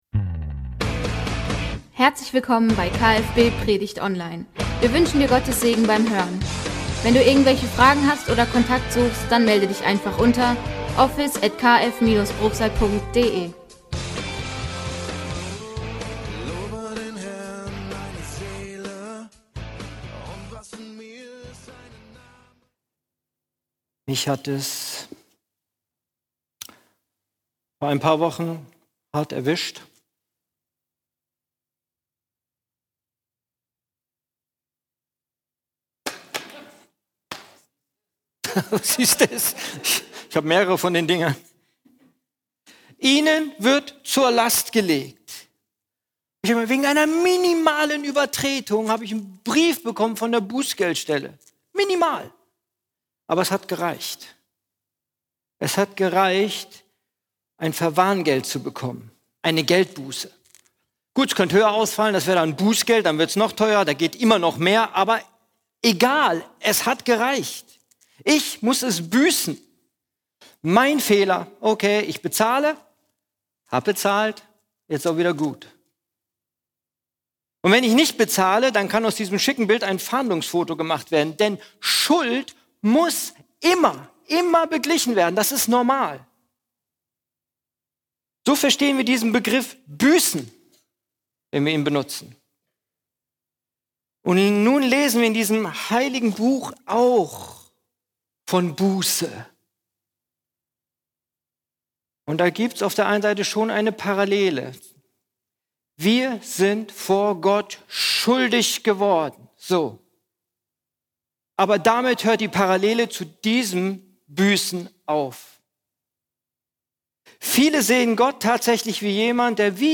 Taufgottesdienst: Die zwei Seiten einer Medaille – Kirche für Bruchsal